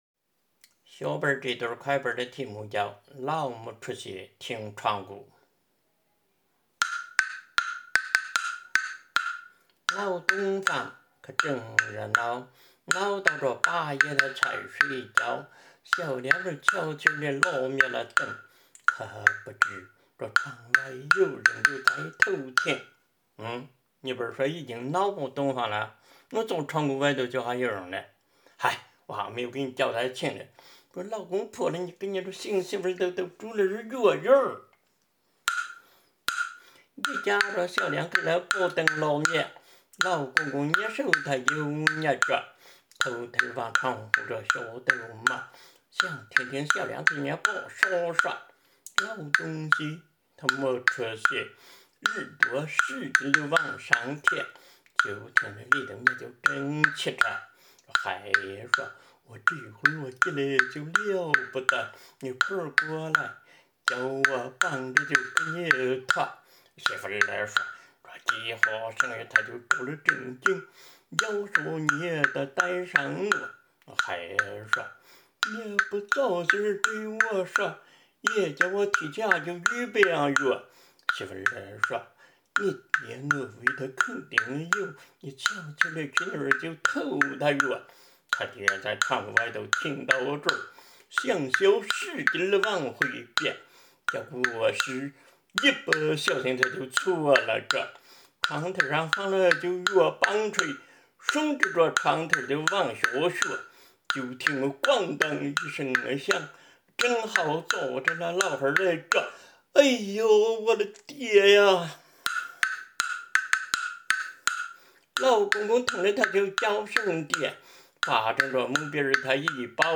【林州方言快板】